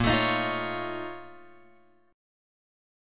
A#mM11 chord {6 x 7 8 6 9} chord